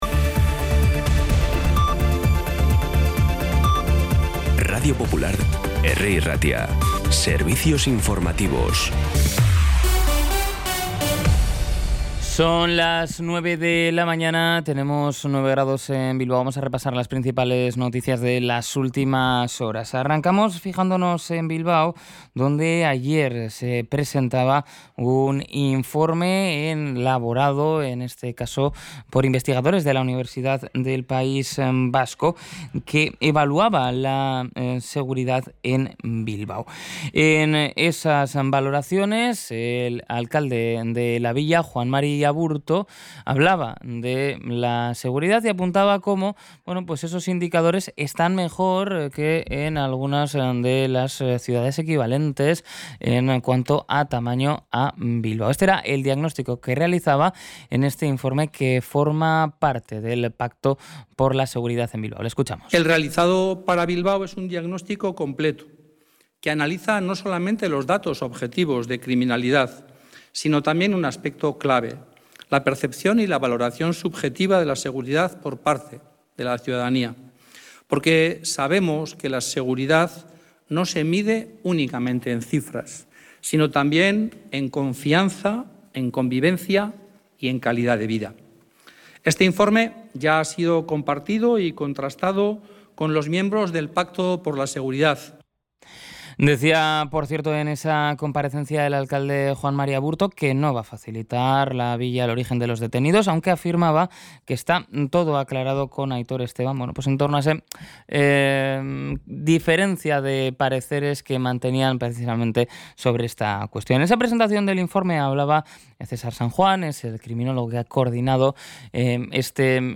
Las noticias de Bilbao y Bizkaia del 19 de febrero a las 9
Los titulares actualizados con las voces del día. Bilbao, Bizkaia, comarcas, política, sociedad, cultura, sucesos, información de servicio público.